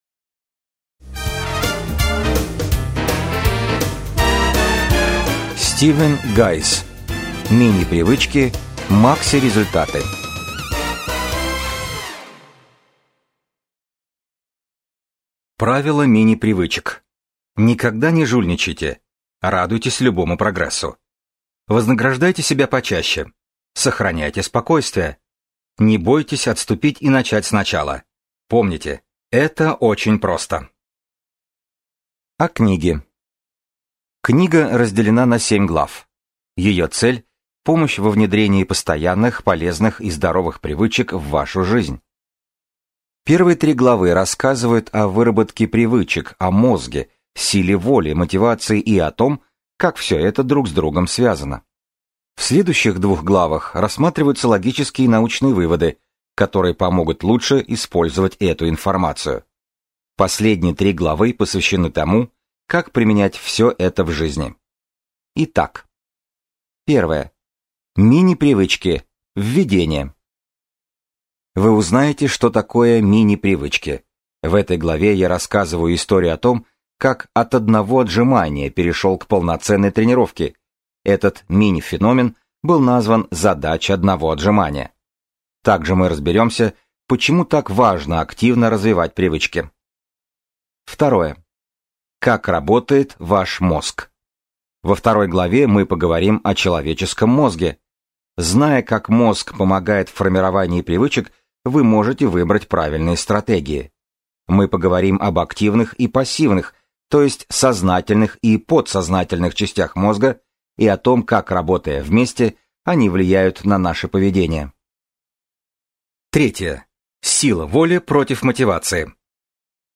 Аудиокнига MINI-привычки – MAXI-результаты | Библиотека аудиокниг